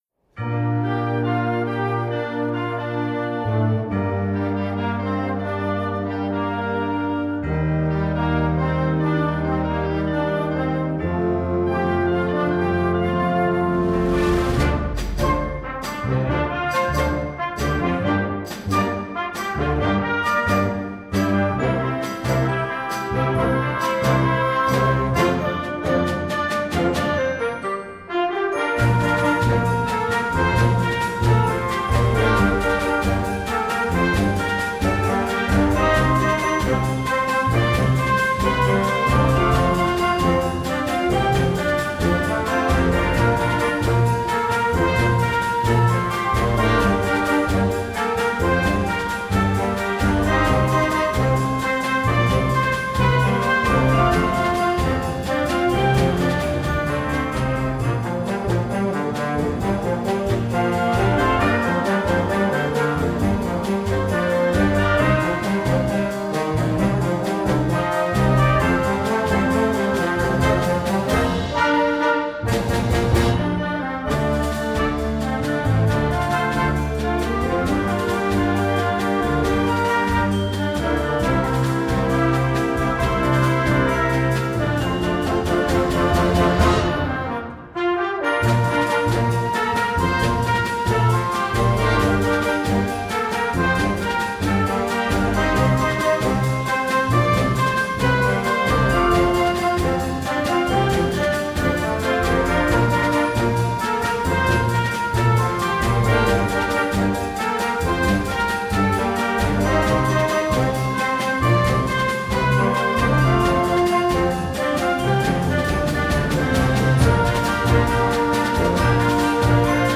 Gattung: Moderner Einzeltitel für Jugendblasorchester
Besetzung: Blasorchester
festlicher und eingängiger Song